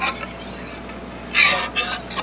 metal_4.wav